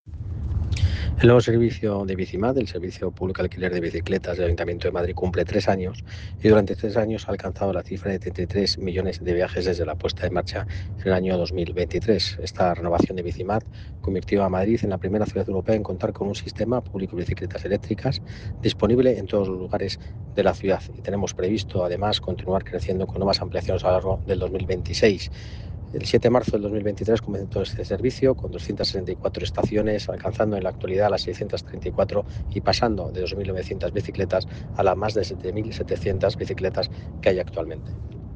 Nueva ventana:El delegado de Urbanismo, Medio Ambiente y Movilidad, Borja Carabante: